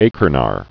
(a_ker_nar)